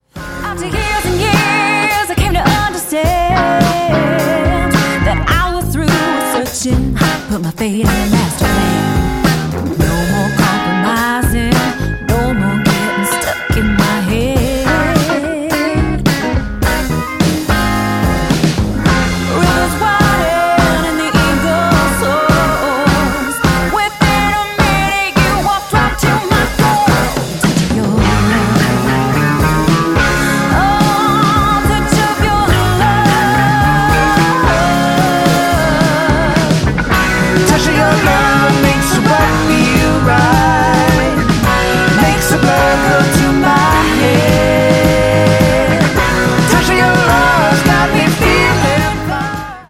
Ottawa blues band, Jazz band & live entertainment music.
is a soulful, fun filled and electrifying musical ride.
drums
bass and backing vocals